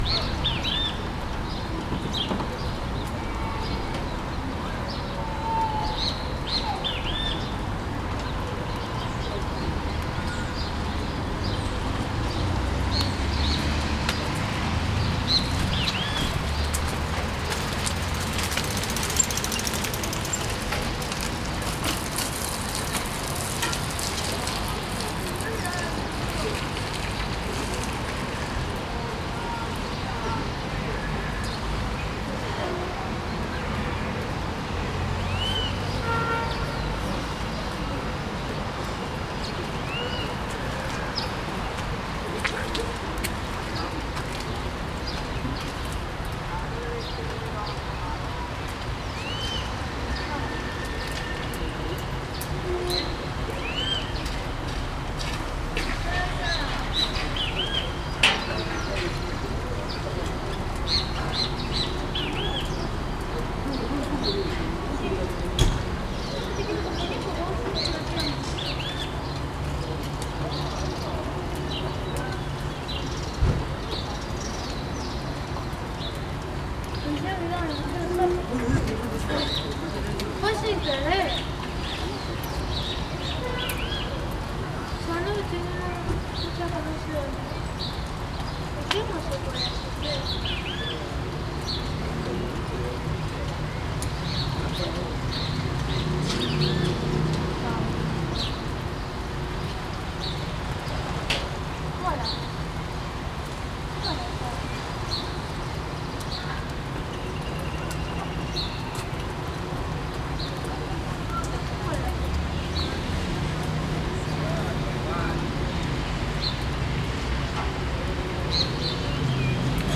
psr-ayacucho-y-ameghino.mp3